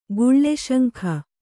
♪ guḷḷe śaŋkh